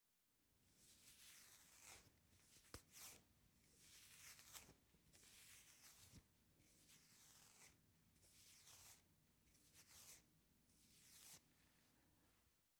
На этой странице вы найдете подборку звуков расчесывания волос – от мягких, едва слышных движений до четких, ритмичных проводок гребнем.
Звук расчёсывания влажных волос после душа